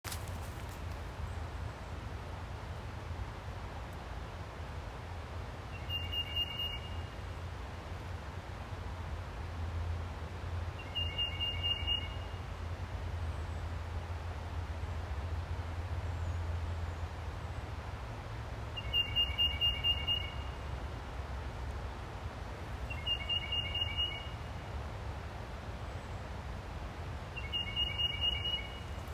Birders think that this bird is saying “Peter, Peter, Peter”. Some people hear it more like “Here, here, here”.
Audio File of Tufted Titmouse calling, CVNP, April 6, 2021. Recording by Conservancy staff.
For example, the Tufted Titmouse and Northern Cardinal can be described as flute-like.
Titmouse-2.m4a